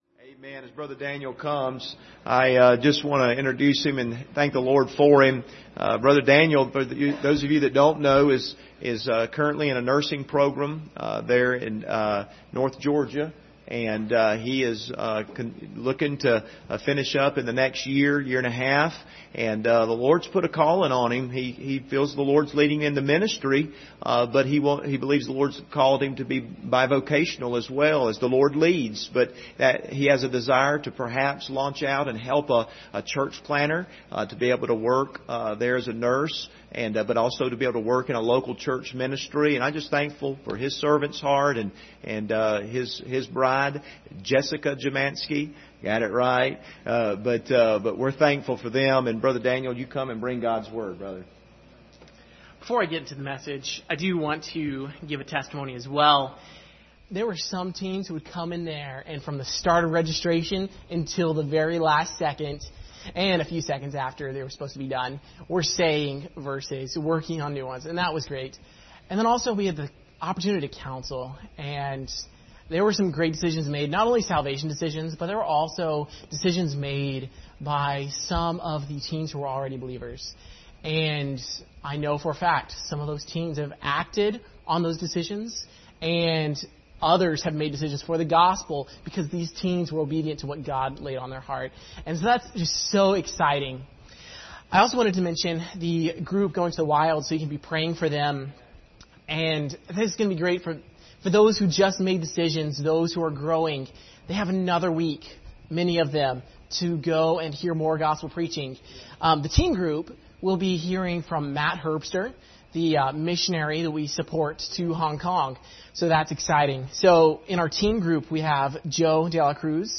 General Passage: 1 Samuel 16:6-12 Service Type: Sunday Evening « Am I a Good Soldier?